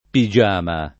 pigiama [ pi J# ma ]